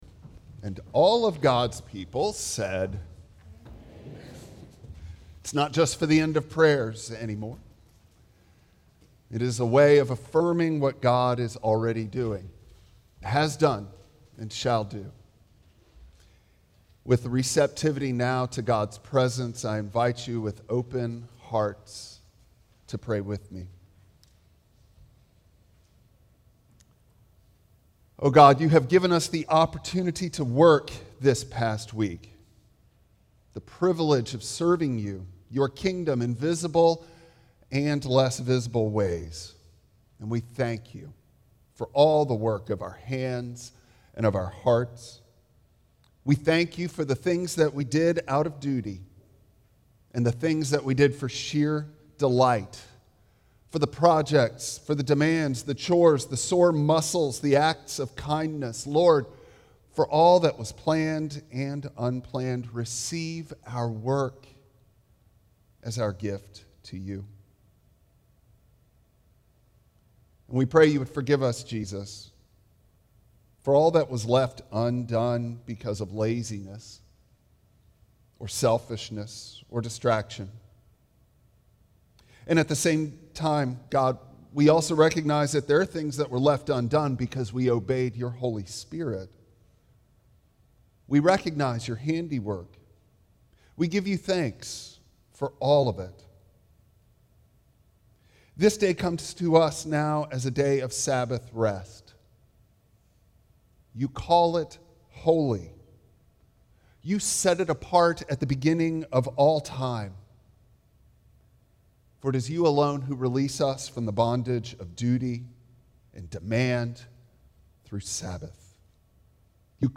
Exodus 5:1-21 Service Type: Guest Preacher Bible Text